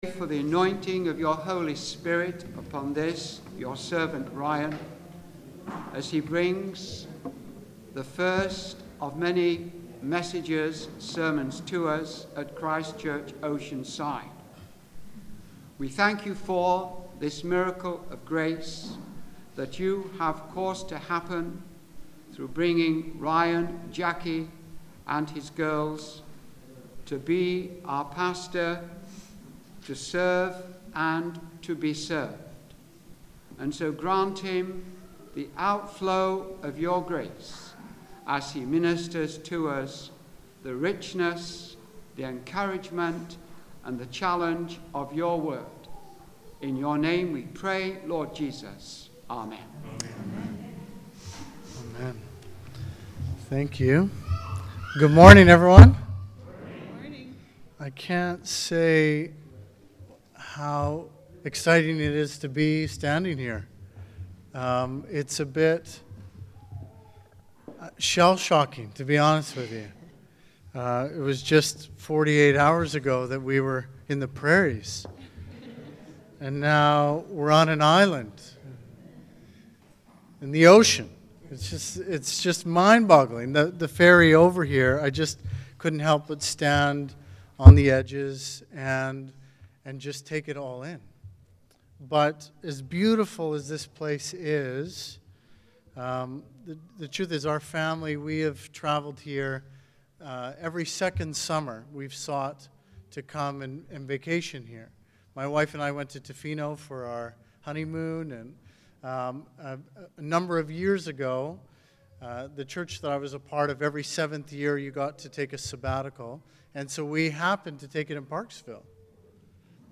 Sermons | Christ's Church Oceanside